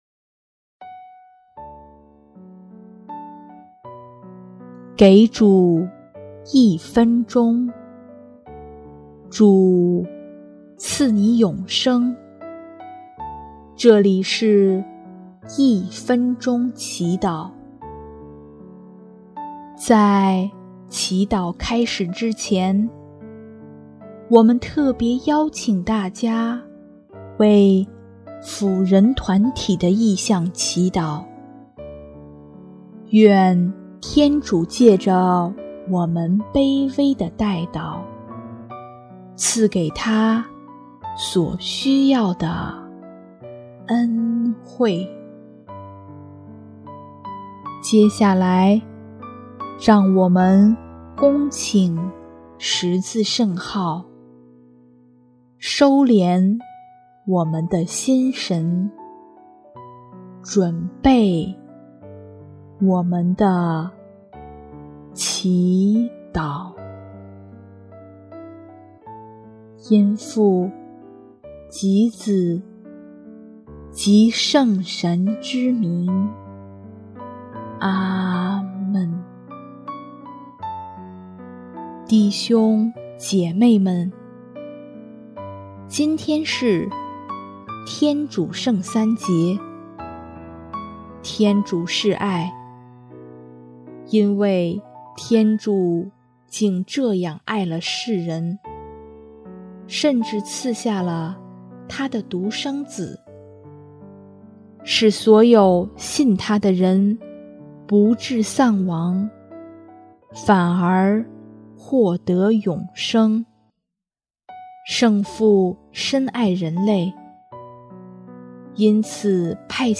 音乐：第三届华语圣歌大赛参赛歌曲《不朽的爱》（FUREN团体：求圣母玛利亚的转祷）